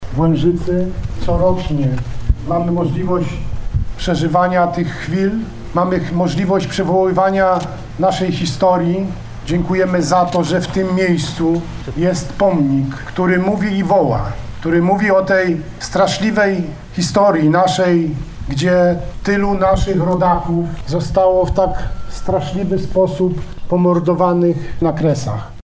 Msza święta, uroczysty apel, odczytanie listów gratulacyjnych i przemówienia. Tak wyglądały XII Uroczystości Kresowe, które odbyły się w niedzielę w Łężycy.